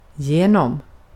Recordings and example transcriptions in this help are in Sweden Swedish, unless otherwise noted.
genom, Göteborg yoyo